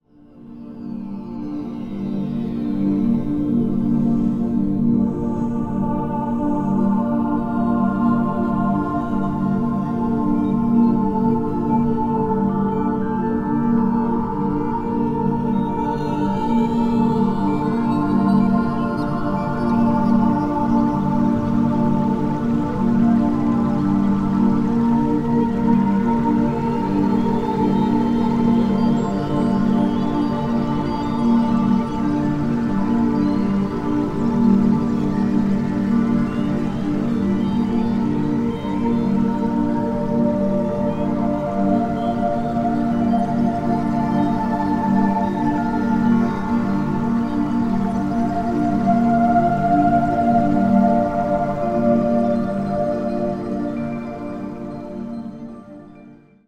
Rebalancing and uplifting.